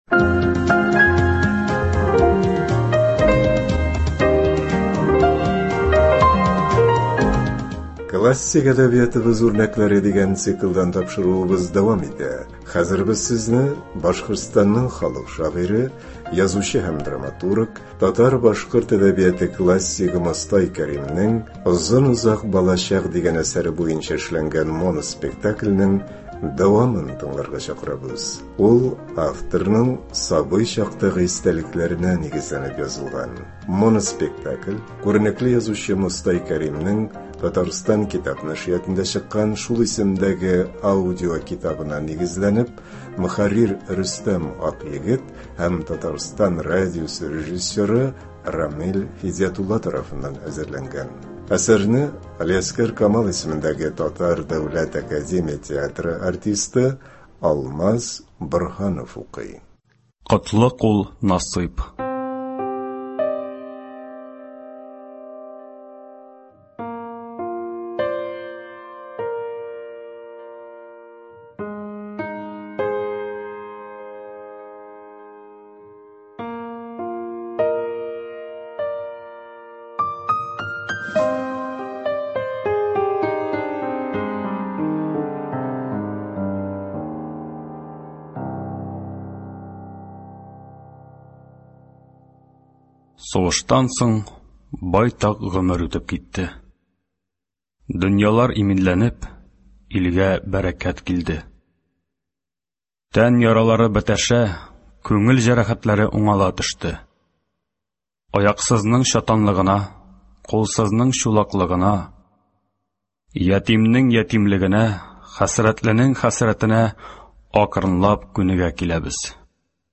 Моноспектакль (31.07.23)